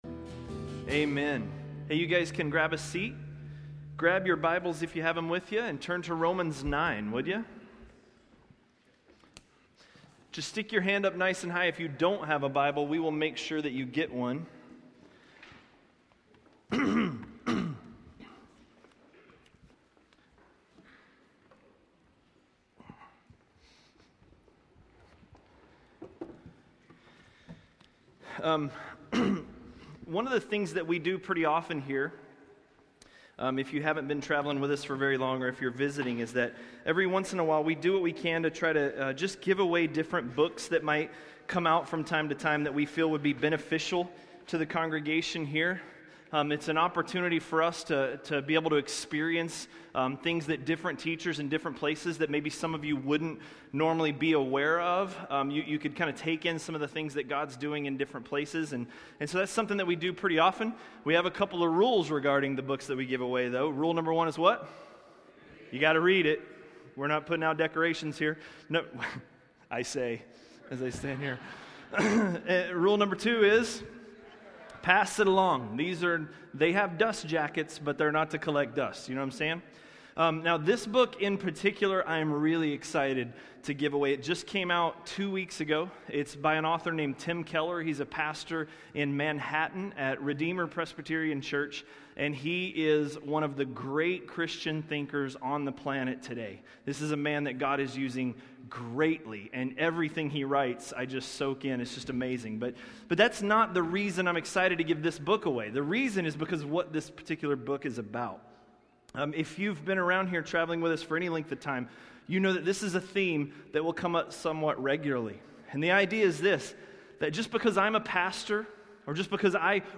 A message from the series "Romans." Romans 9:1–9:5